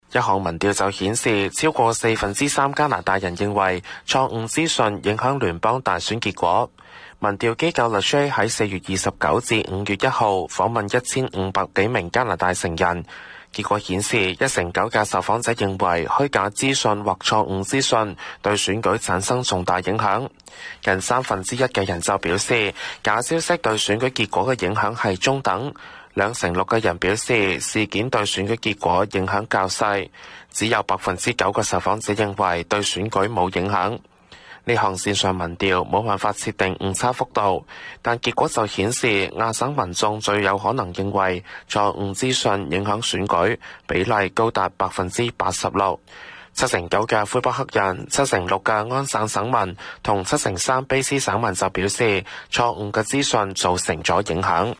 news_clip_23385.mp3